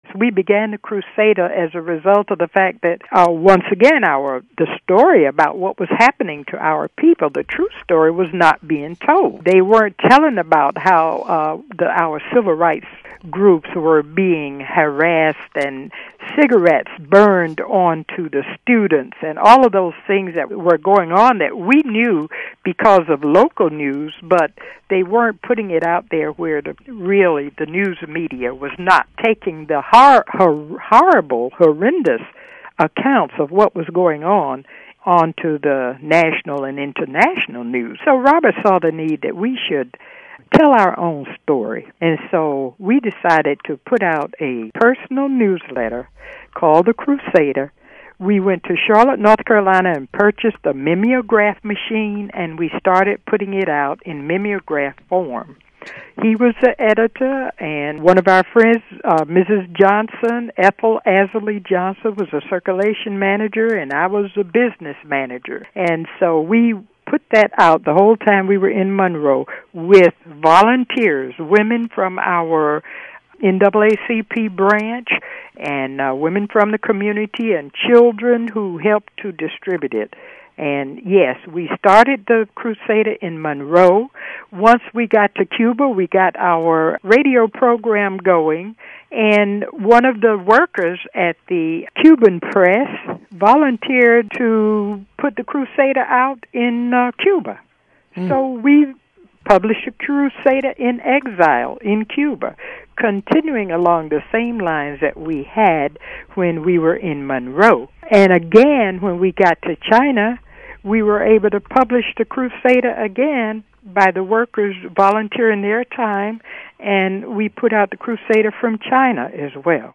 from an interview